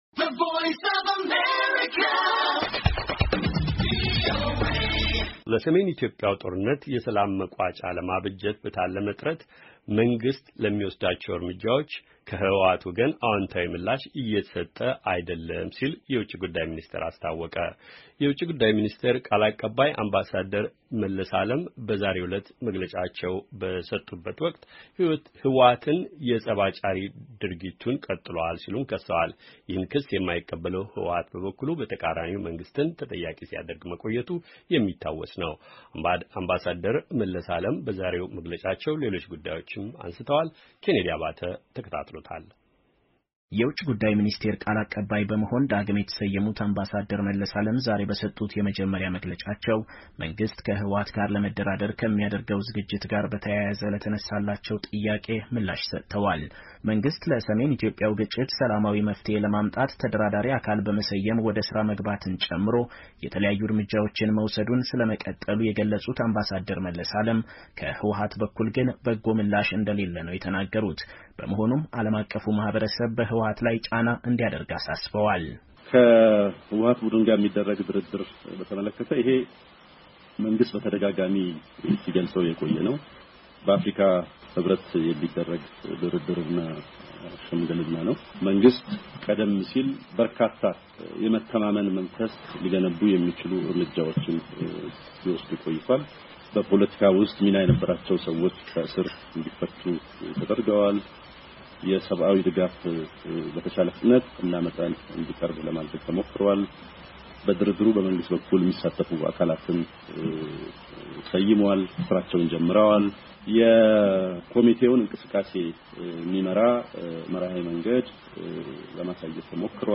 አምባሳደር መለስ አለም በዛሬው መግለጫቸው ሌሎች ጉዳዮችንም አንስተዋል፡፡